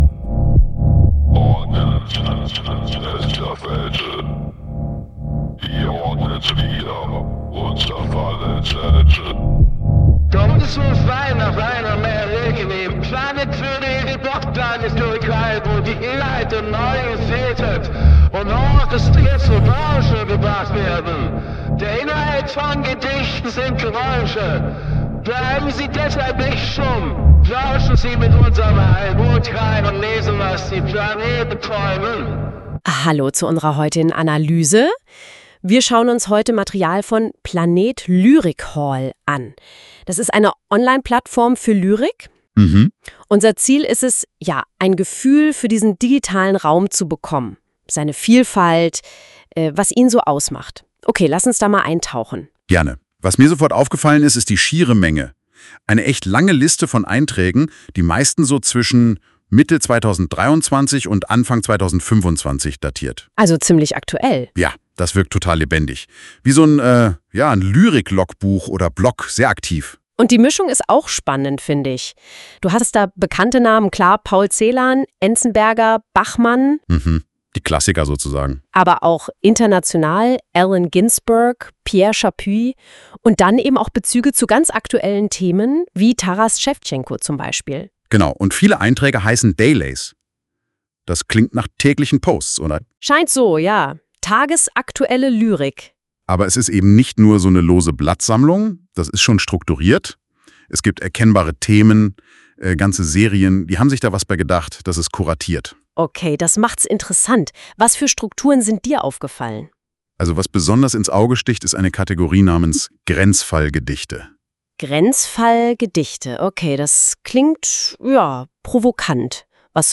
Komplett mit KI (Gamechanger NotebookLM + In/Outro der Studiokünstler:in Udio) erstellter Podcast zur PlanetLyriHallWebseite